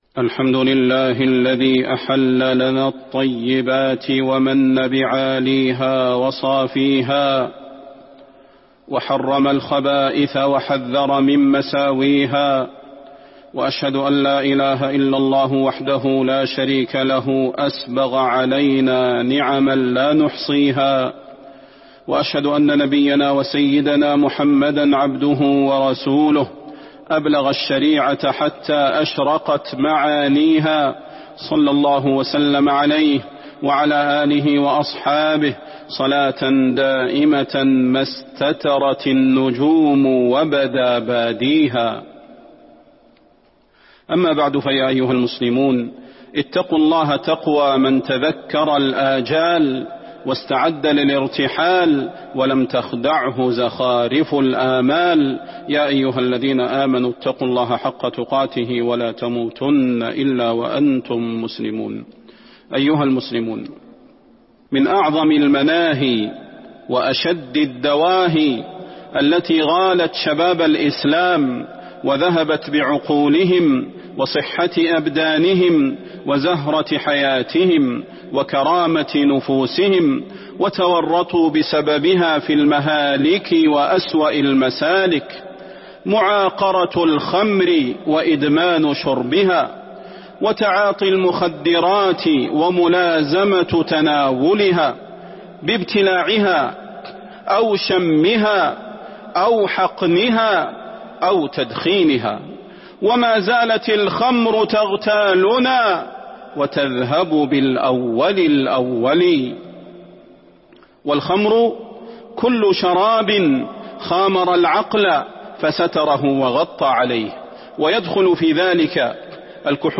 تاريخ النشر ١٦ شوال ١٤٤٢ هـ المكان: المسجد النبوي الشيخ: فضيلة الشيخ د. صلاح بن محمد البدير فضيلة الشيخ د. صلاح بن محمد البدير آفة الآفات الخمور والمخدرات